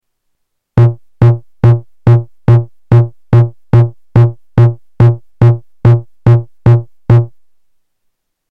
AirBase 99 bassdrum 1
Category: Sound FX   Right: Personal